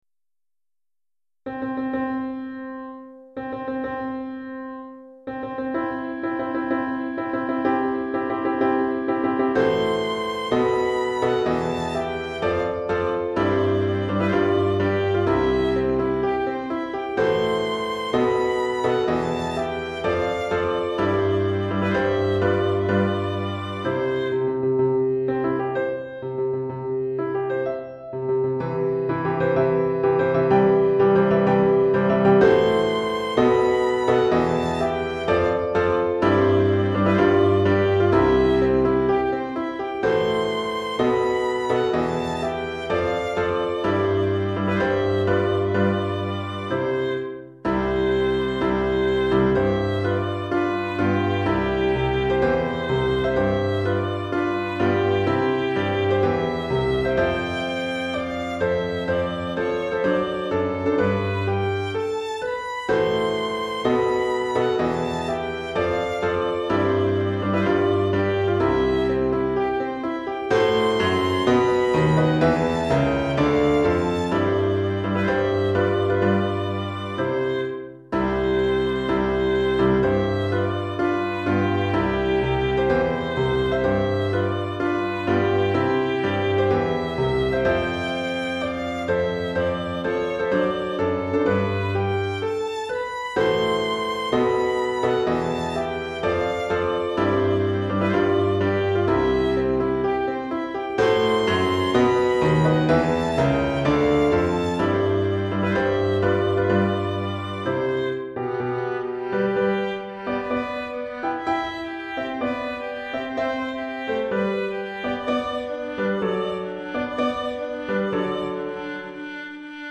Violon et Piano